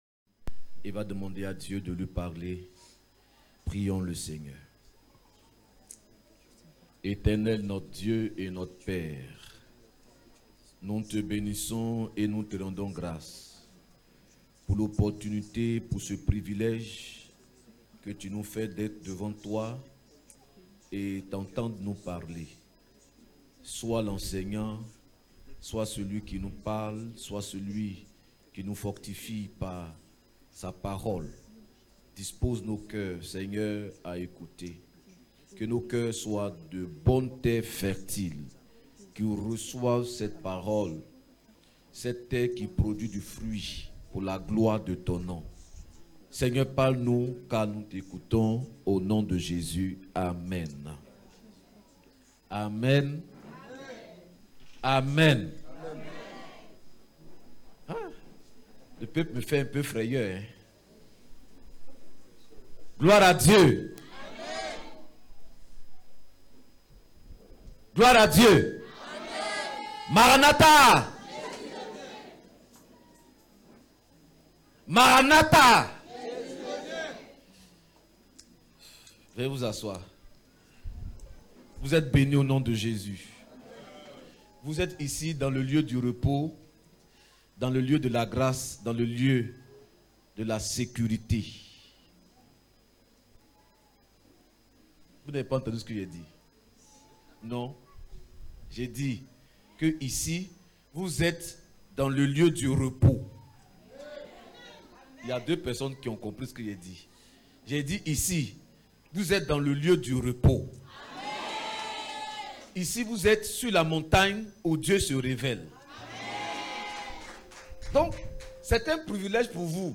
9 mai 2021 Se fortifier dans la bonne résolution Prédicateur: Rév.